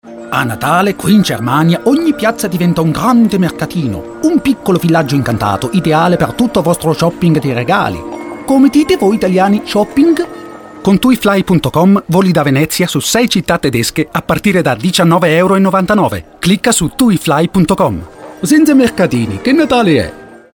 italienisch
Sprechprobe: Industrie (Muttersprache):
voice over artist italian.